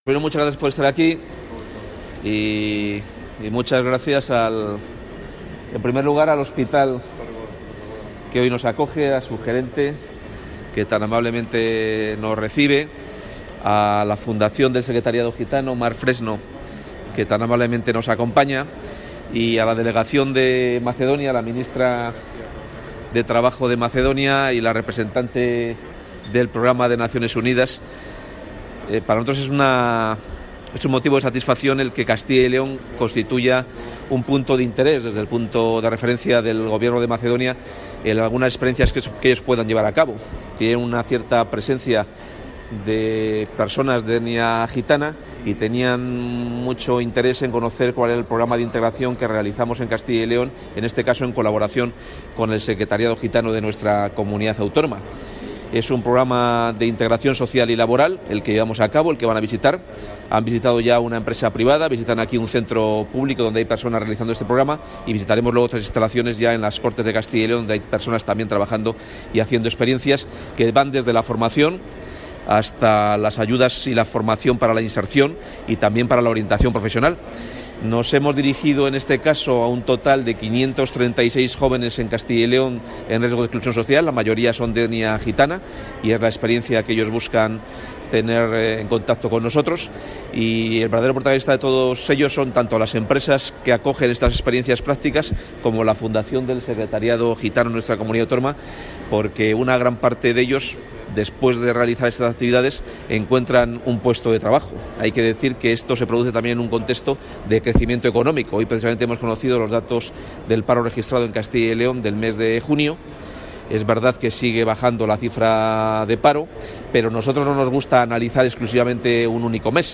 Declaraciones del consejero de Empleo.